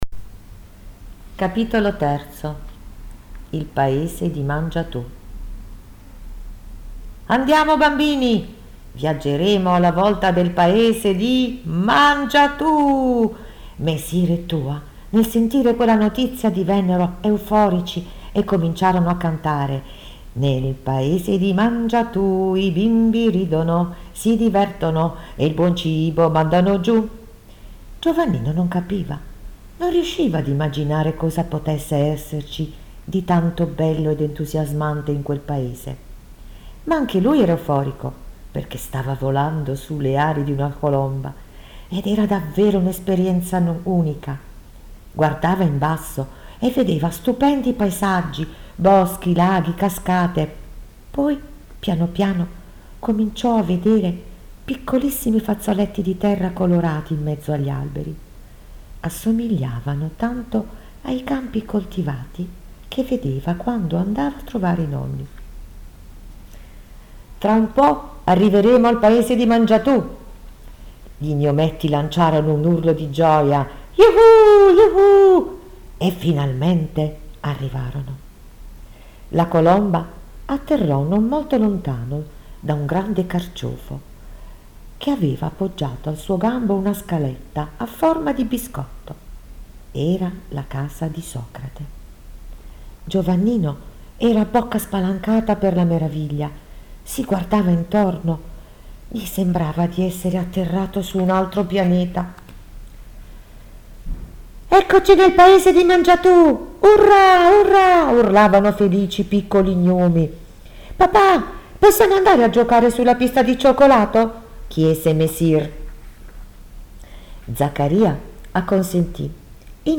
DISPONIBILE ANCHE IN AUDIOLIBRO